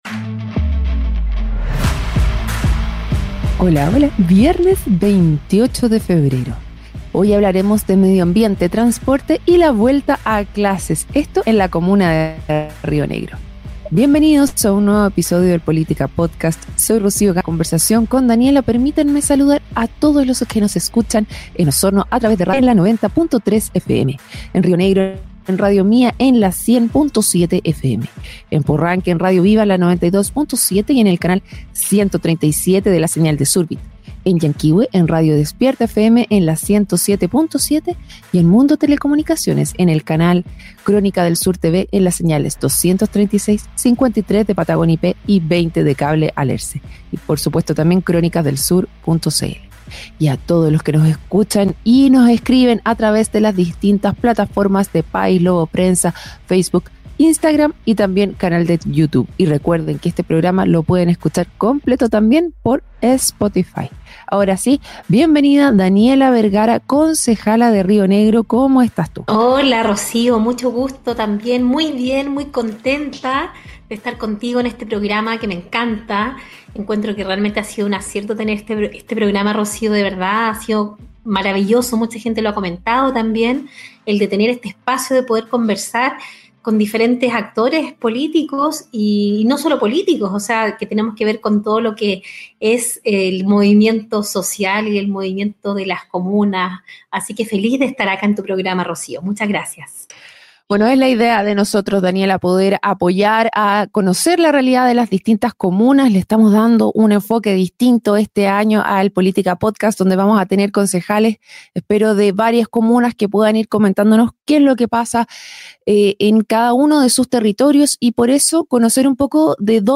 En un nuevo episodio del Política Podcast, la concejala de Río Negro, Daniela Vergara, abordó temas clave para la comuna, destacando iniciativas en medio ambiente, transporte y educación.